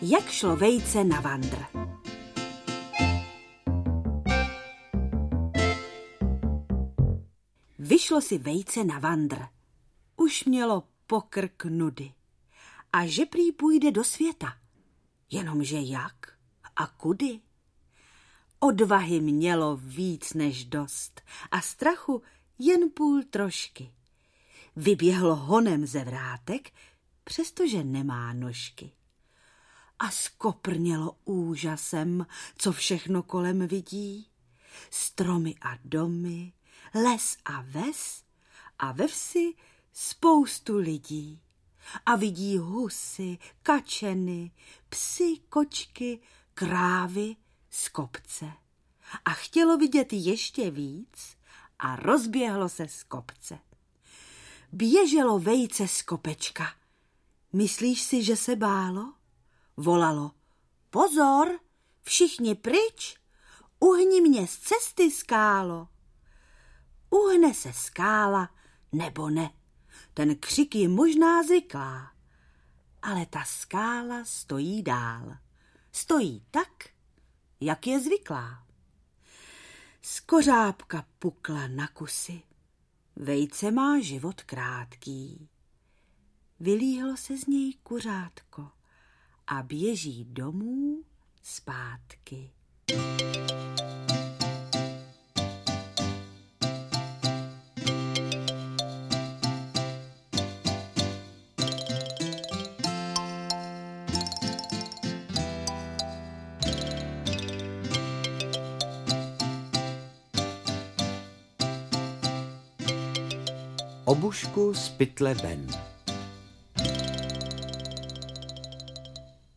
Počítání oveček audiokniha
pocitani-ovecek-audiokniha